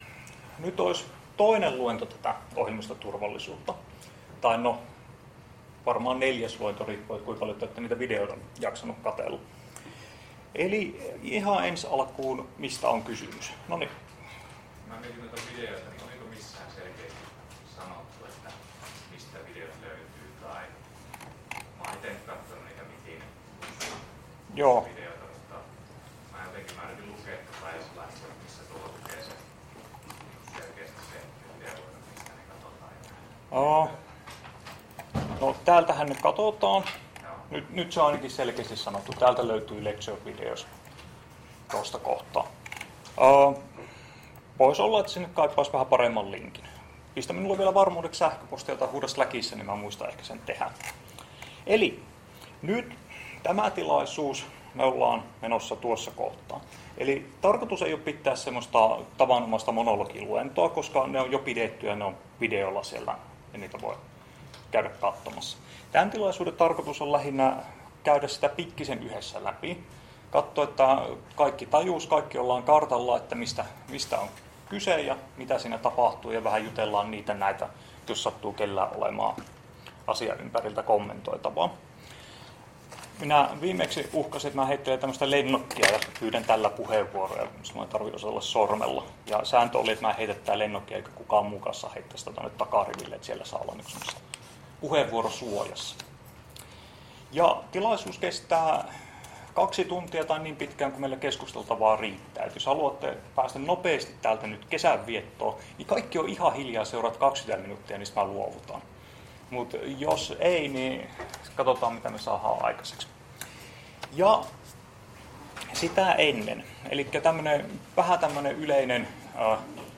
Luento 29.5. — Moniviestin